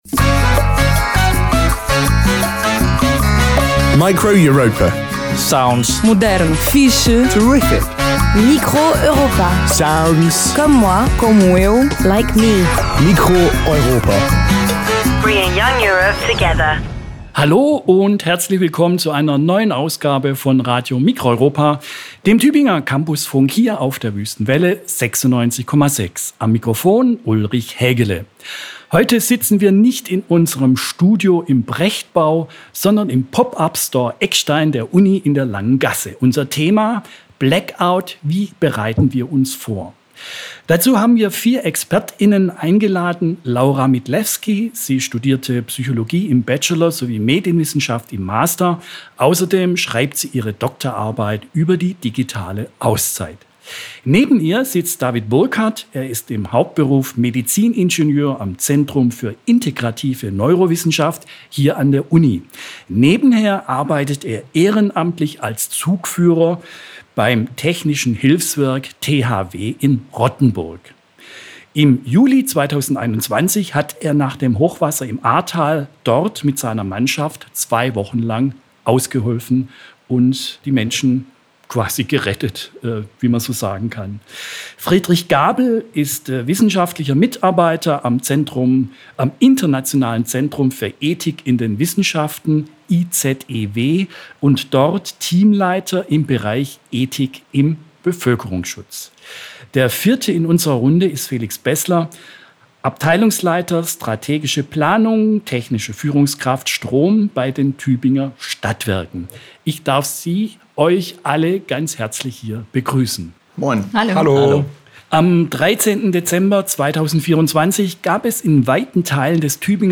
Blackout: Wie können wir uns vorbereiten? Sendung aus dem Tübinger Pop Up Store der Uni
Heute sitzen wir nicht in unserem Studio im Brechtbau, sondern im Pop Up Store Eckstein der Universität in der Langen Gasse.
Dazu haben wir vier Expert*innen eingeladen: